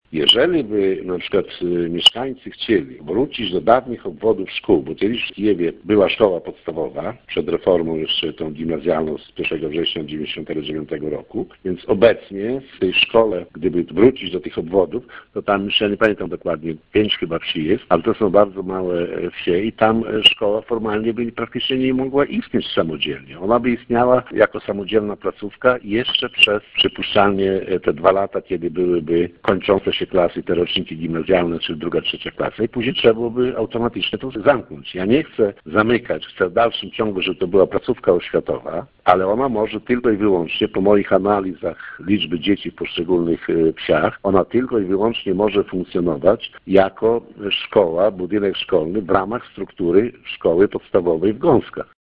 Jak dodaje burmistrz utworzenie w Kijewie samodzielnej szkoły podstawowej ze względu na niewielką ilość uczniów doprowadziłoby do zamknięcia jej w przeciągu dwóch lat- tłumaczy.